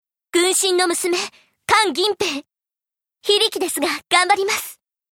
關銀屏（CV：三上枝織）